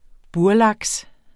Udtale [ ˈbuɐ̯- ]